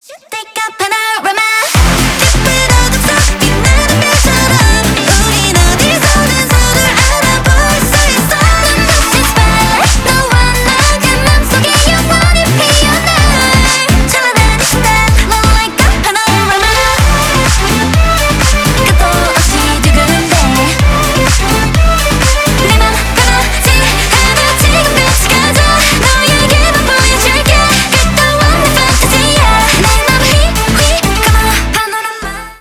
ритмичные